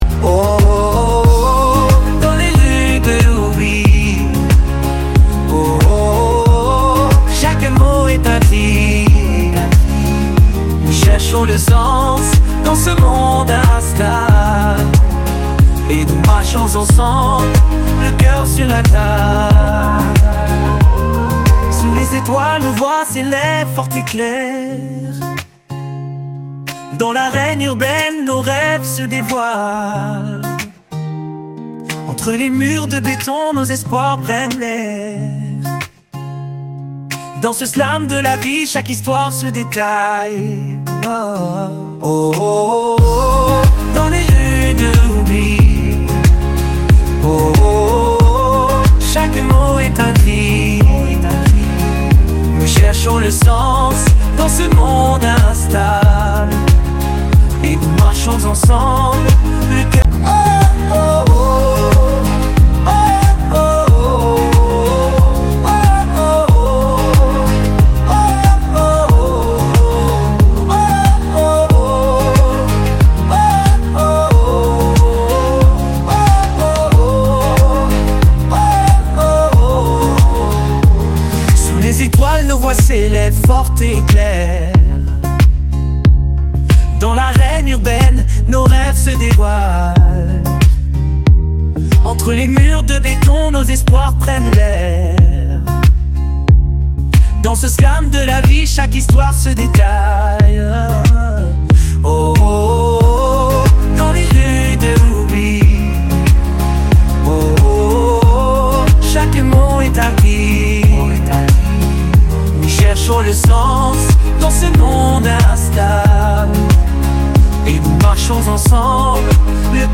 Écouter le refrain de ce poème en chanson (4.58 Mo)